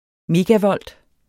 Udtale [ ˈmeːga- ]